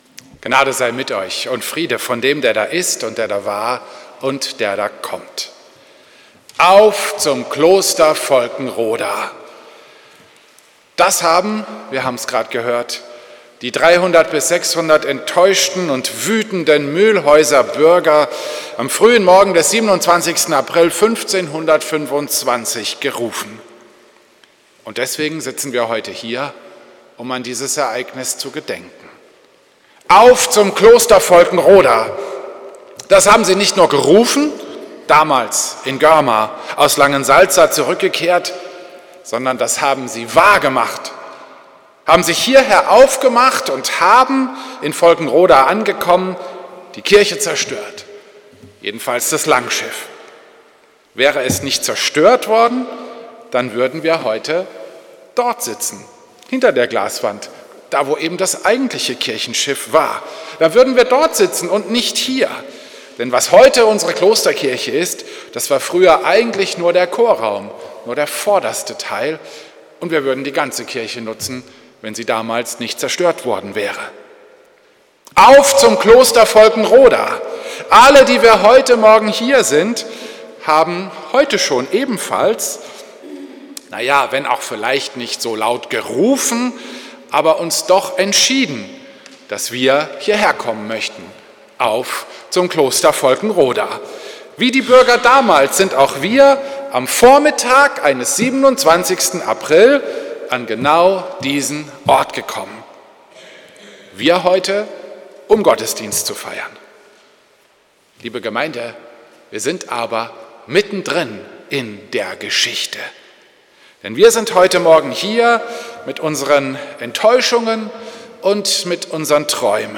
Predigt am Sonntag Quasimodogeniti über 1. Petrus 1,3-9 zum Gedenken an den 500. Jahrestag der Zerstörung des Klosters Volkenroda